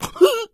PigHit 14.wav